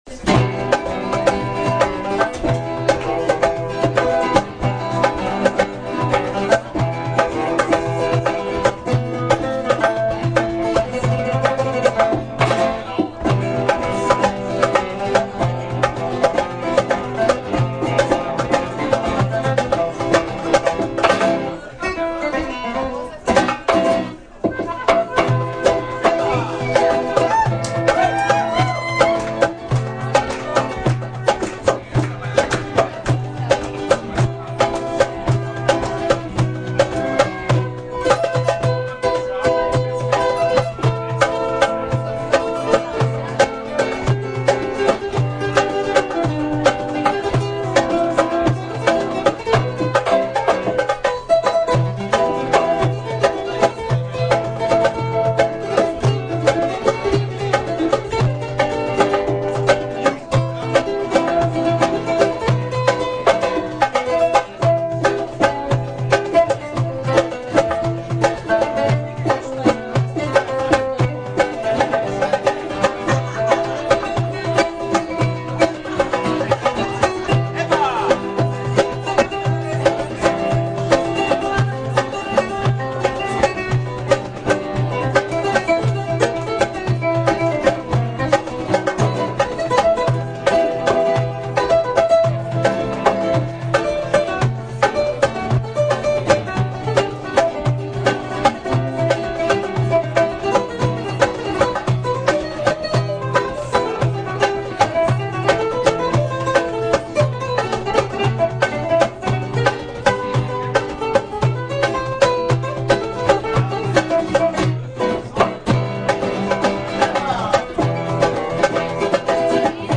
! Extraits du concert au format mp3 !